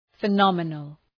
Προφορά
{fı’nɒmənəl}